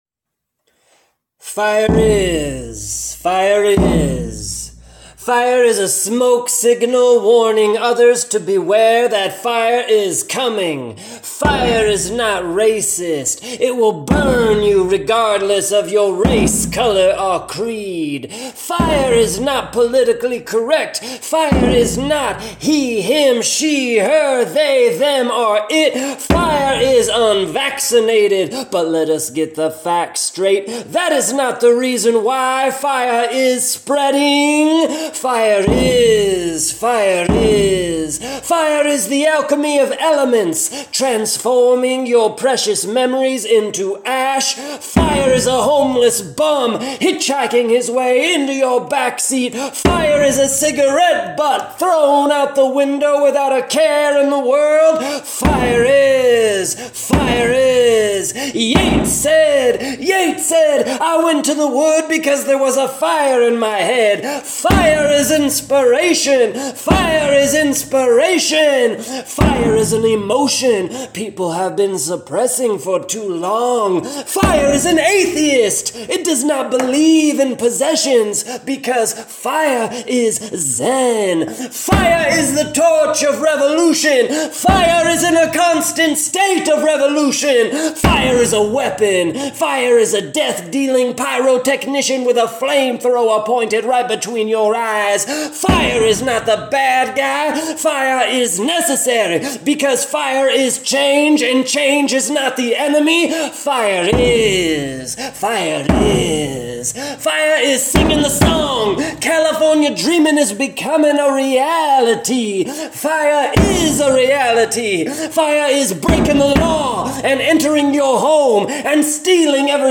Parental advisory: this poem contains explicit content.